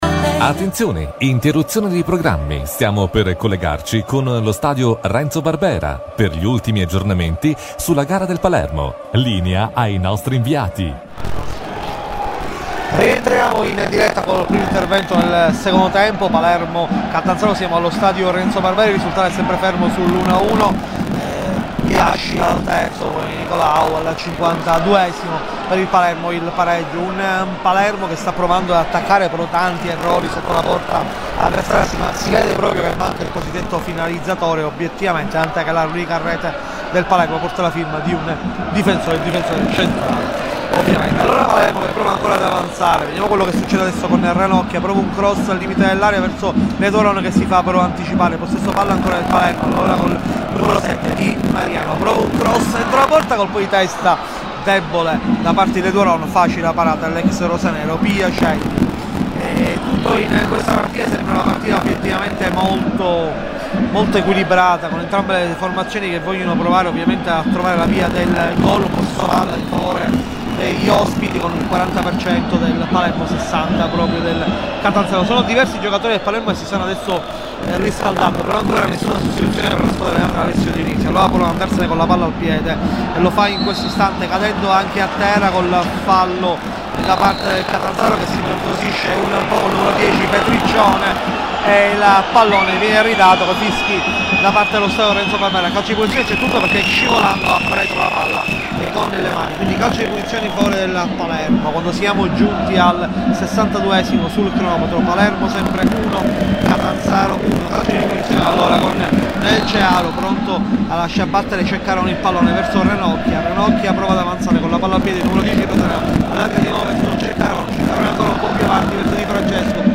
problemi rumore coollegamento mblive in esterna
da un paio di sxettimane non riousciamo più a fare i coollegamenti con mb live dal nostro cellulare, da anni hanno sempre funzionato, adesso si collerga funziona ma l'audio è tutto tremolante a scatti, non riusciamo a capire quale sia il problema e siamo nei guai perche non possiamo garantire i collegamenti, cosa potrebbe creare qeasto problema?